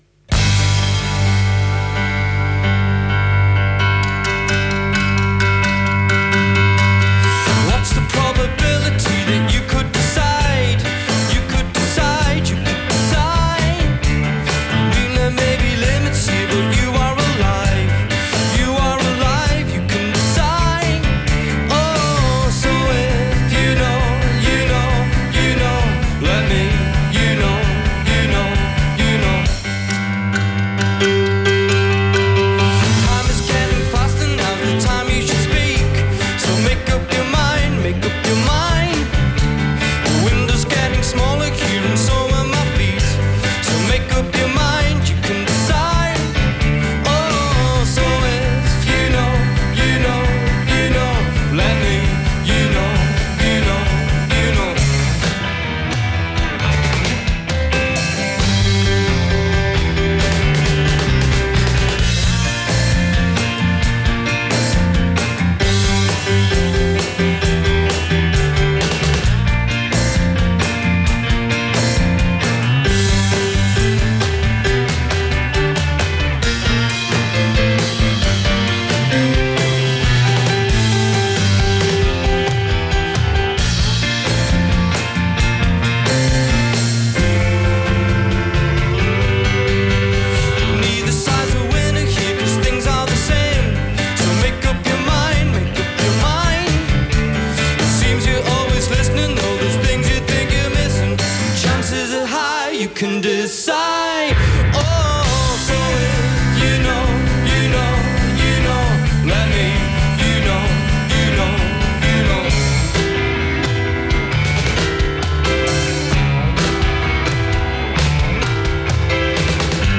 enregistrée le 22/02/2010  au Studio 105